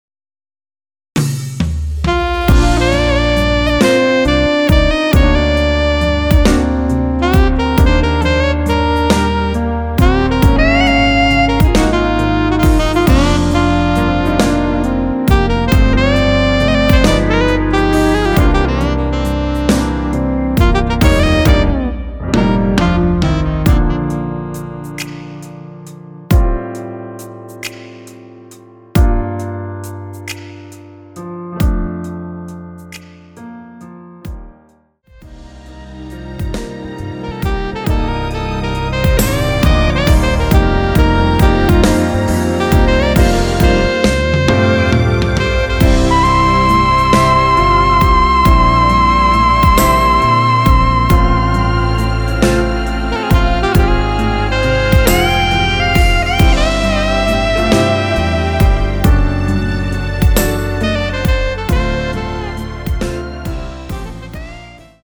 원키에서(-1)내린 MR입니다.
Bb
앞부분30초, 뒷부분30초씩 편집해서 올려 드리고 있습니다.
중간에 음이 끈어지고 다시 나오는 이유는